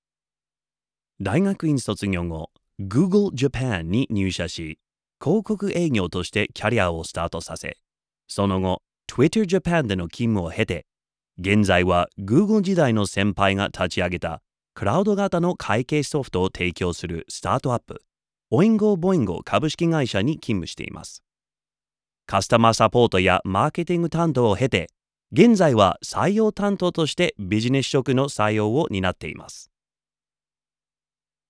バイリンガル司会・MC
ボイスサンプル
ゲストの経歴紹介　MC